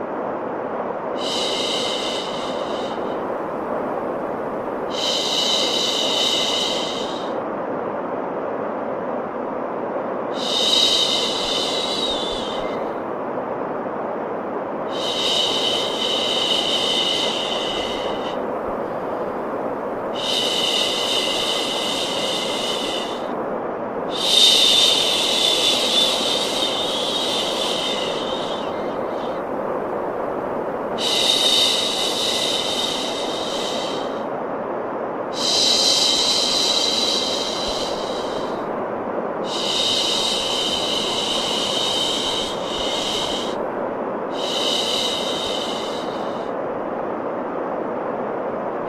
1_2_shhh_white_noise.m4a